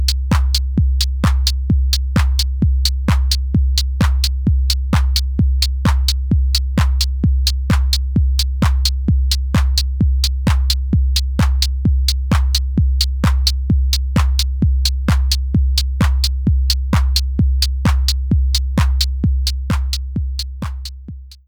0ms:
You’ll notice that with claps, it’s relatively unimportant. With two kicks on top of each other, it becomes problematic.